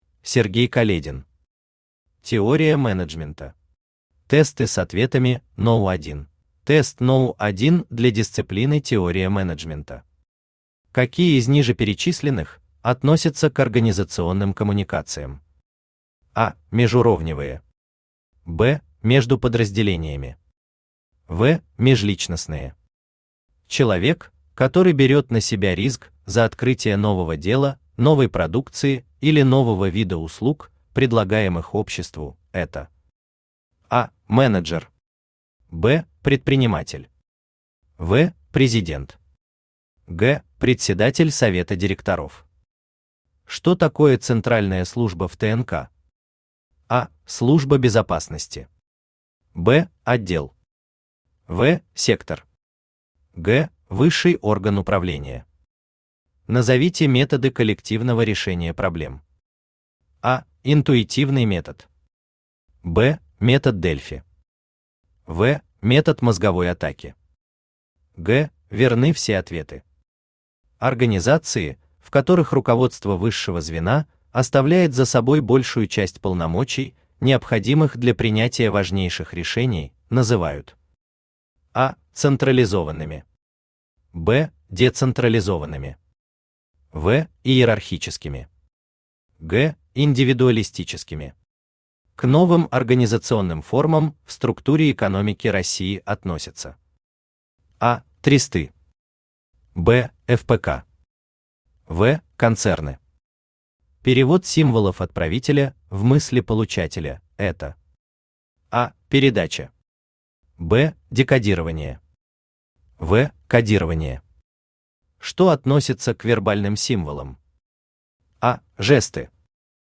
Аудиокнига Теория менеджмента. Тесты с ответами № 1 | Библиотека аудиокниг
Тесты с ответами № 1 Автор Сергей Каледин Читает аудиокнигу Авточтец ЛитРес.